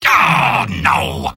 Robot-filtered lines from MvM. This is an audio clip from the game Team Fortress 2 .
{{AudioTF2}} Category:Soldier Robot audio responses You cannot overwrite this file.
Soldier_mvm_negativevocalization04.mp3